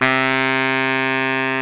Index of /90_sSampleCDs/Roland LCDP06 Brass Sections/BRS_Pop Section/BRS_Pop Section1
SAX B.SAX 15.wav